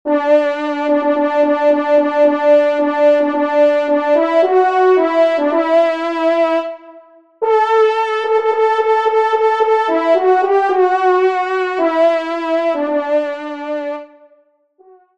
Genre : Musique Religieuse pour  Quatre Trompes ou Cors
Pupitre 1°Trompe